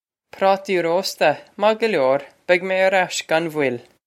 Pronunciation for how to say
Praw-tee row-sta, mah guh lyore! Beg mey air ash gon vwill.
This is an approximate phonetic pronunciation of the phrase.